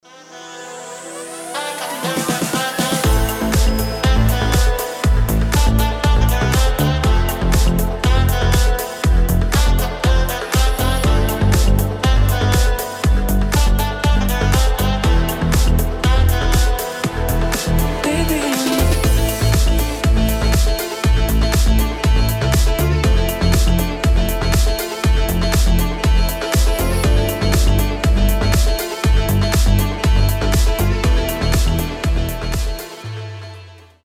• Качество: 320, Stereo
deep house
мелодичные
Саксофон